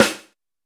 SNARE 900.WAV